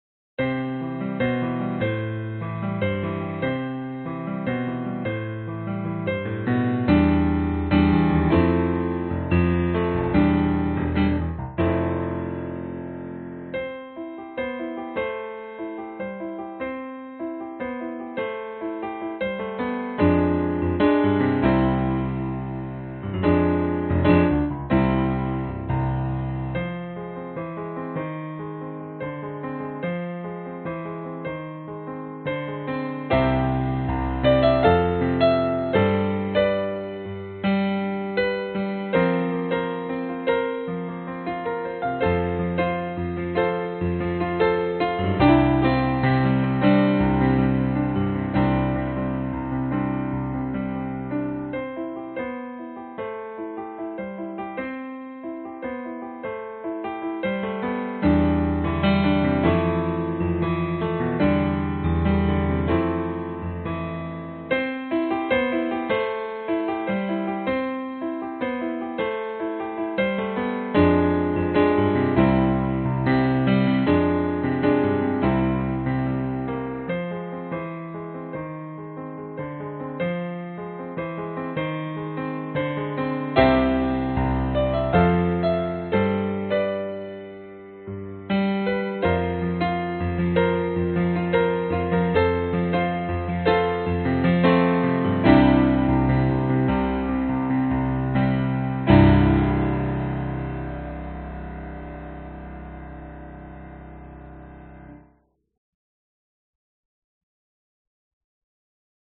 描述：一首原创的欢快的流行歌曲的钢琴独奏表演。
BPM约为75，但我忘了具体数字。
Tag: 原声 明亮 现代 器乐 钢琴 表演 独奏 主题 欢快 振奋人心